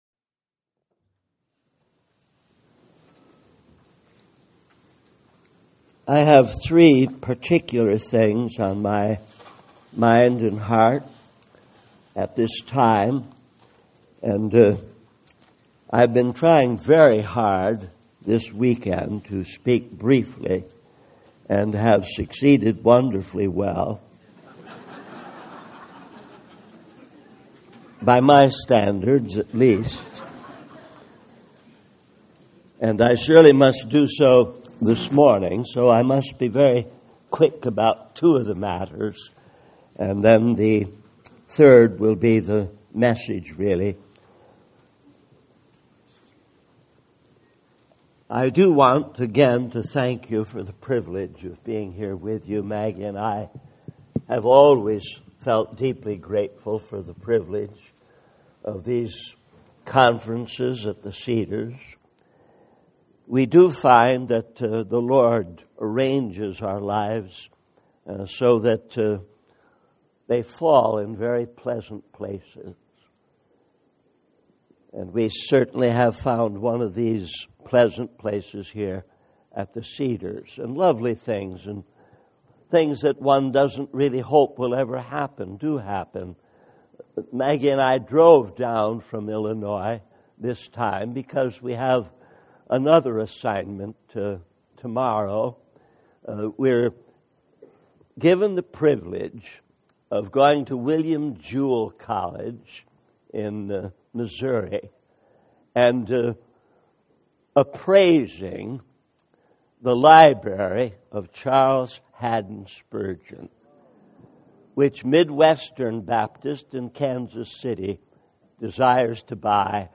In this sermon, the speaker emphasizes the importance of understanding God's love and hatred. He encourages the audience to study the Bible and search for references to God's love and hatred to shape their thinking and behavior.